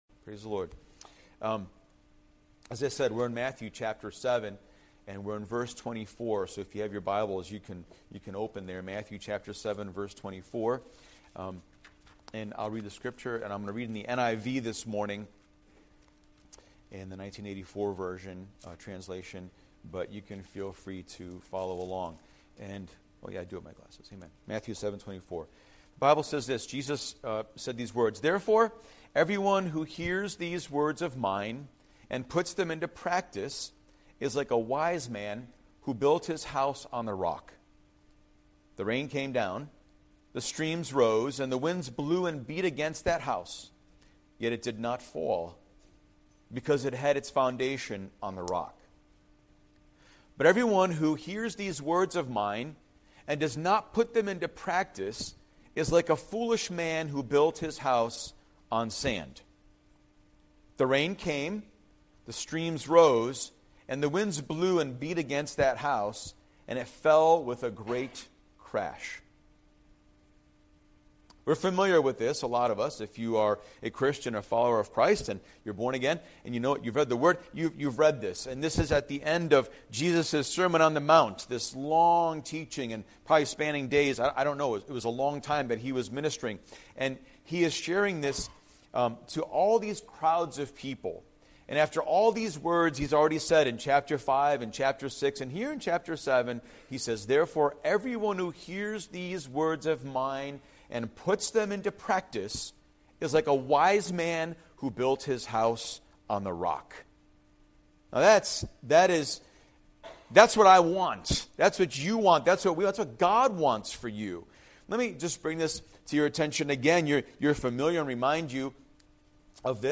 Weekly sermons from New Hope Chapel, Carolina, RI., an evangelical community church.